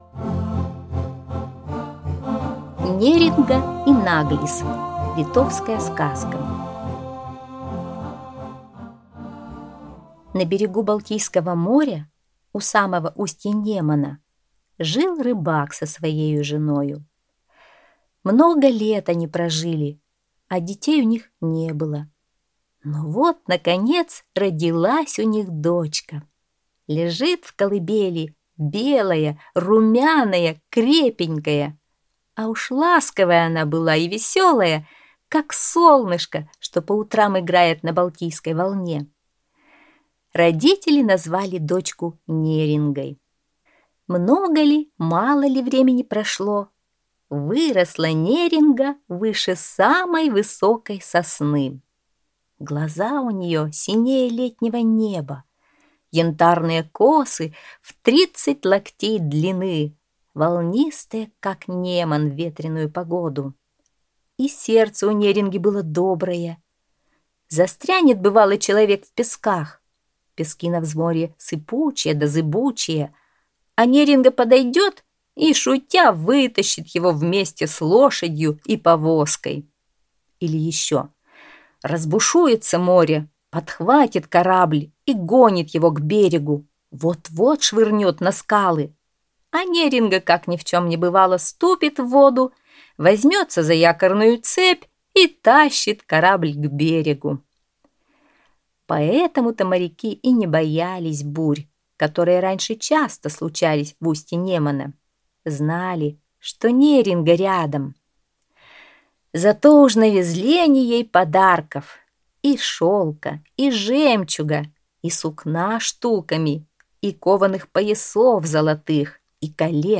Неринга и Наглис - литовская аудиосказка - слушать скачать